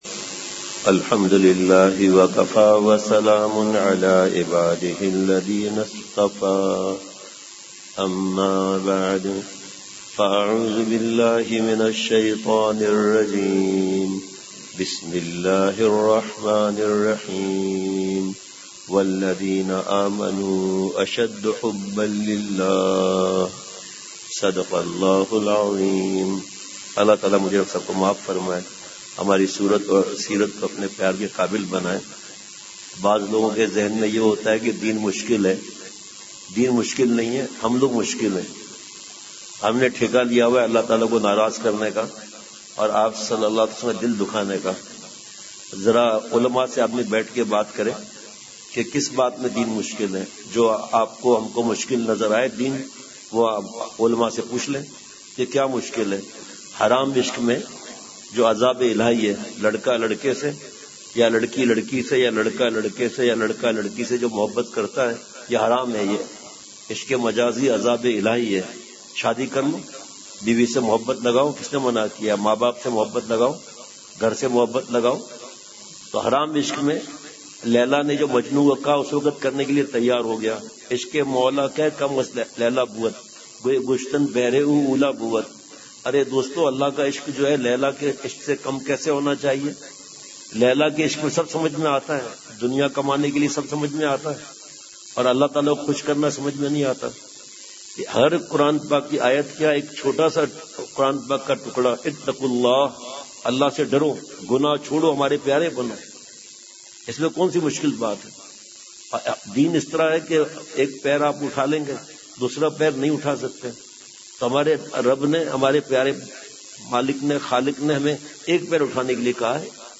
بمقام مدنی مسجد حبیب آباد پشاور
بیویوں کے حقوق، اور موبائل سے متعلق۔ پورا بیان بہت ہی جوش میں تھا۔ اور انداز جس طرح پشتو زبان والے اردو بولتے ہیں بہت ہی انداز میں مٹھاس اور شفقت تھی۔